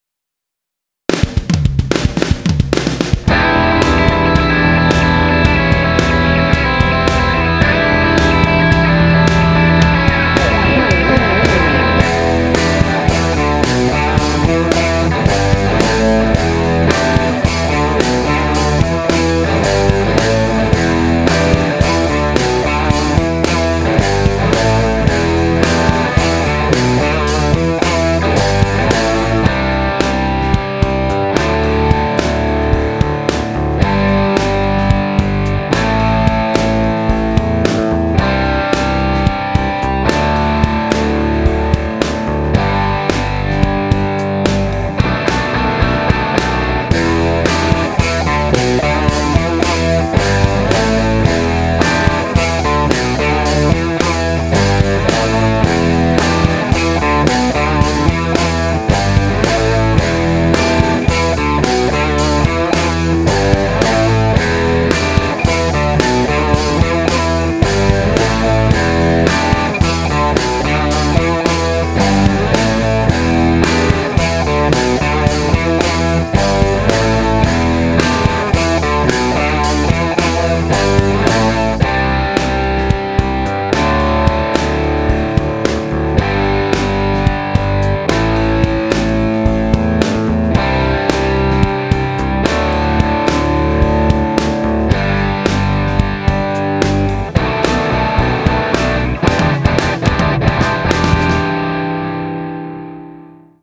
podkład i nagrać pod niego swoją solówkę (szczegóły w regulaminie).
Konkurs kierowany jest do gitarzystów i polega na zagraniu solo gitarowego do podkładu przygotowanego przez MUSIC STORE.